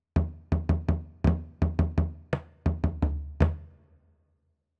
Luckily, I found suitable bodhran samples online at FMJ Software and used these to create a velocity-sensitive EXS24 instrument hosted in MainStage.
bodhran.m4a